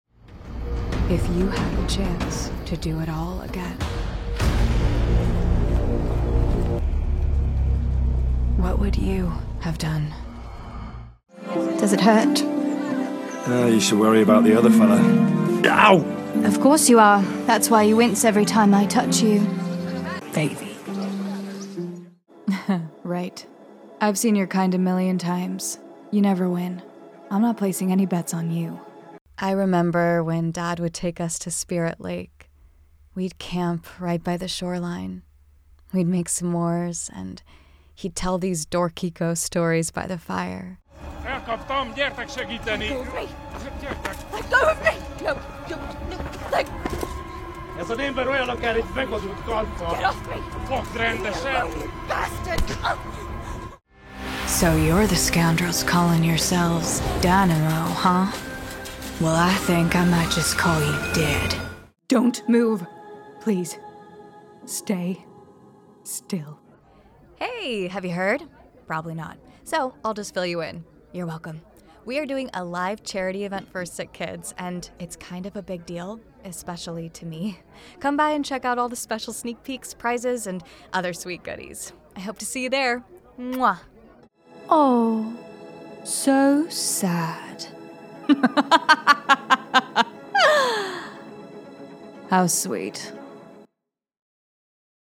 Videogame and Animation Reel